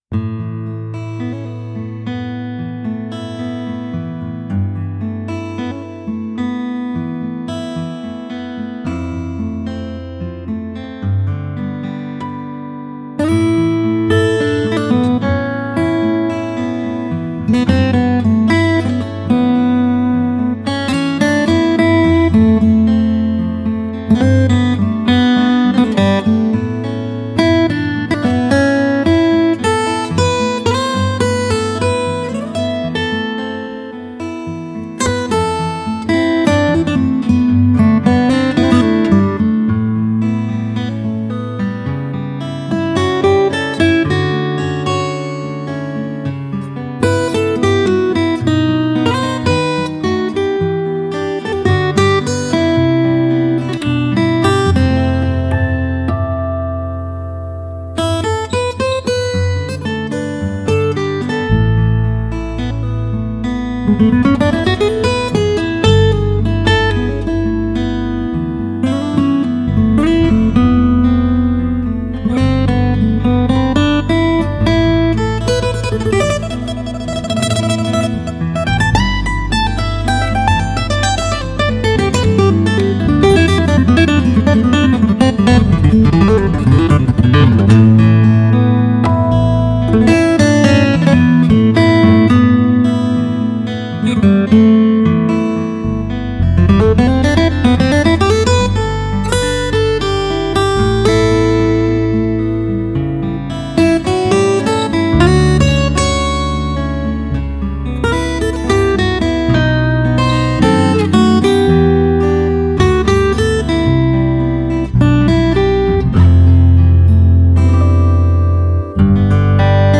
Famous, bright, and unmistakable Taylor guitar sound